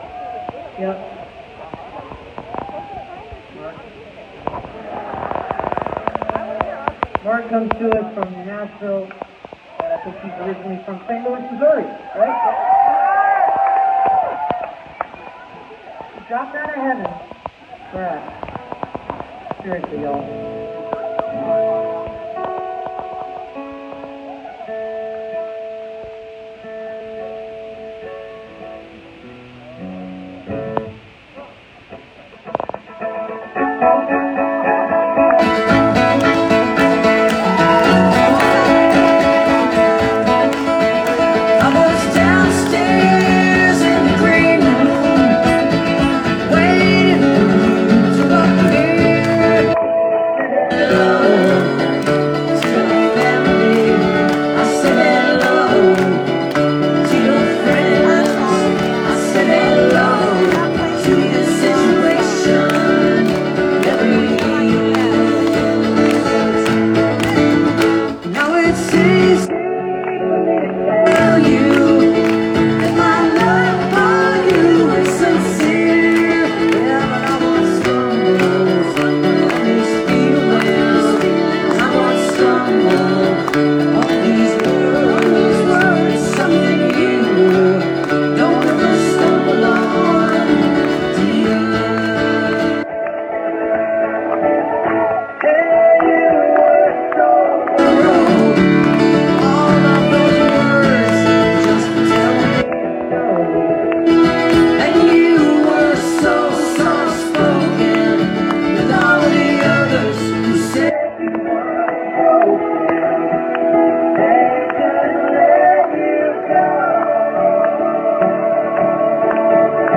(captured from a facebook live stream)